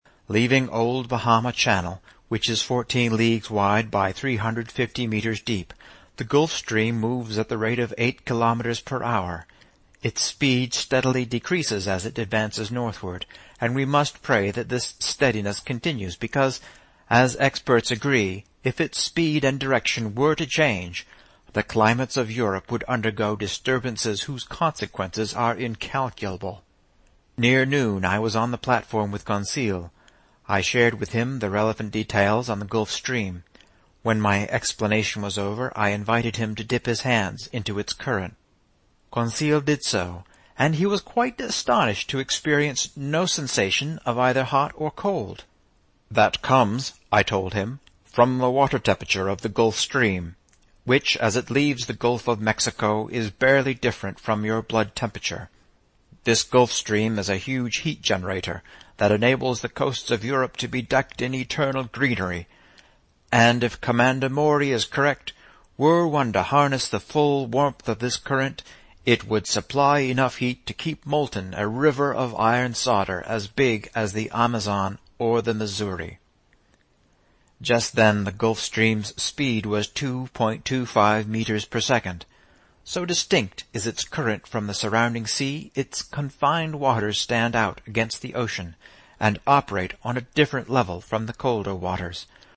英语听书《海底两万里》第513期 第32章 海湾暖流(4) 听力文件下载—在线英语听力室